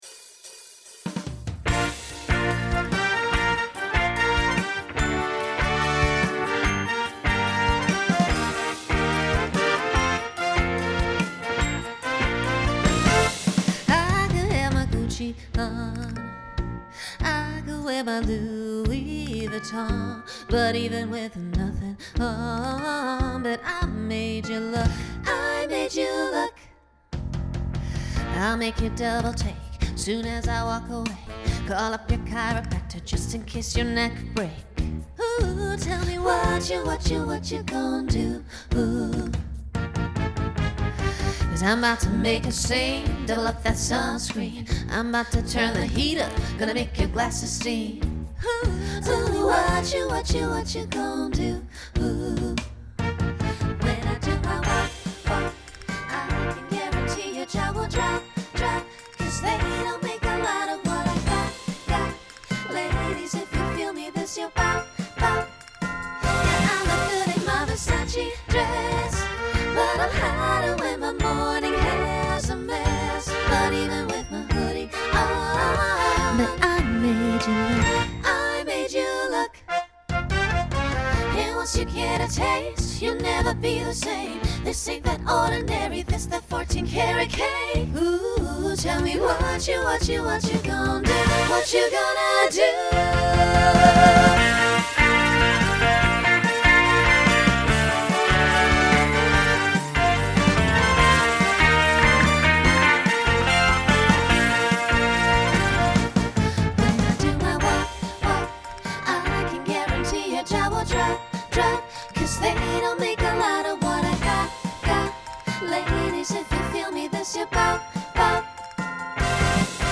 Genre Pop/Dance
Transition Voicing SSA